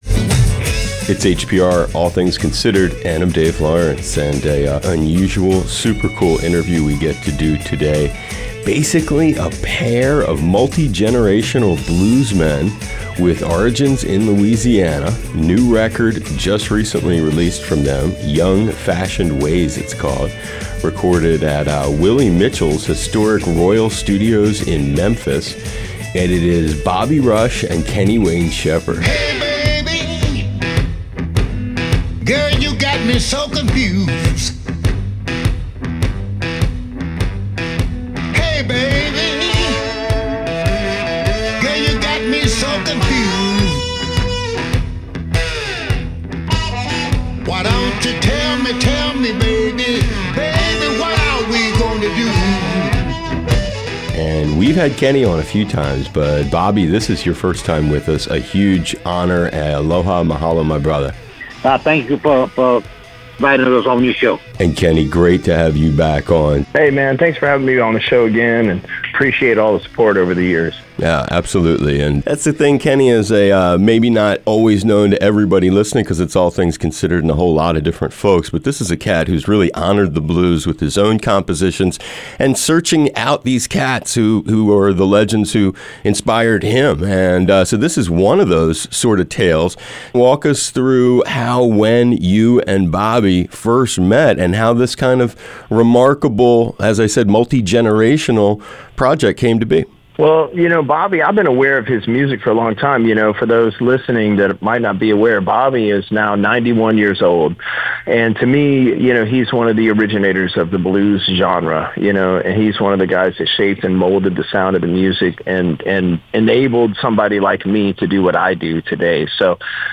Road Stories is a series of interviews with musicians from across the musical spectrum, sharing their experiences during the pandemic, new projects, classic stories and exclusive musical performances.